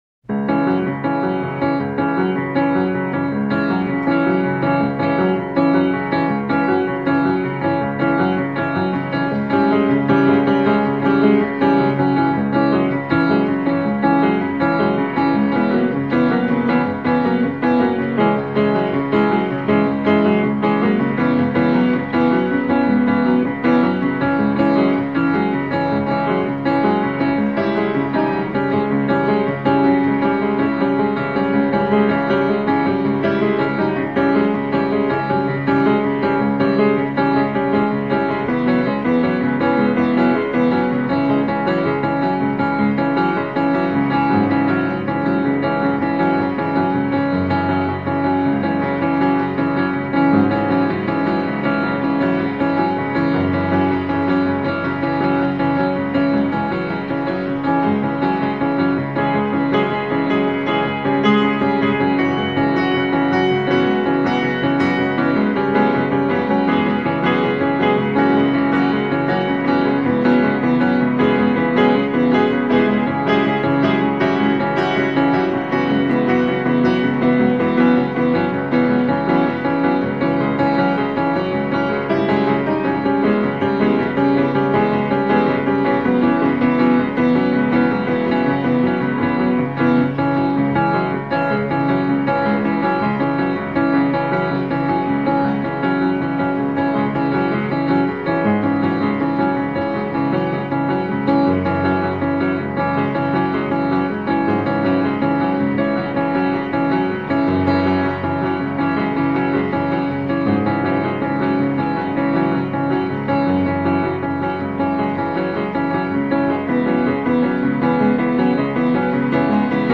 Piano, synthesizer and processed strings